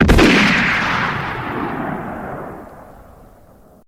Repeater Fire 2 Téléchargement d'Effet Sonore